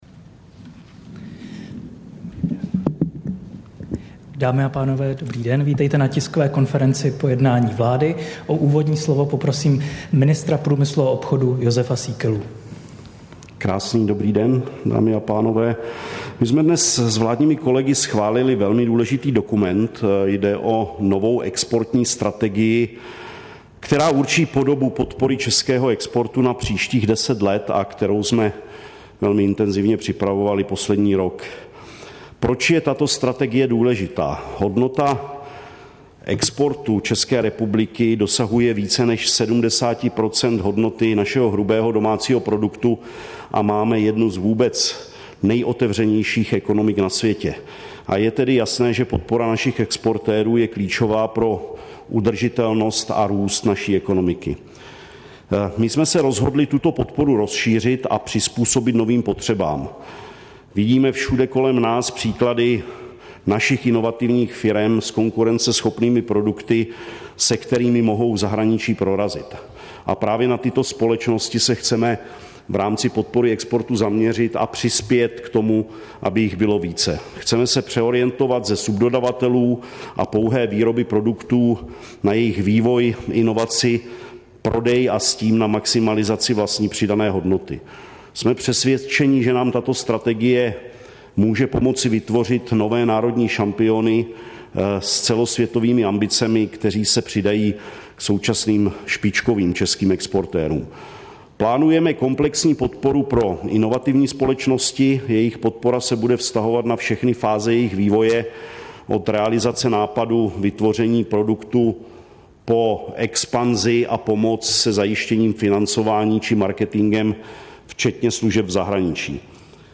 Tisková konference po jednání vlády 26. července 2023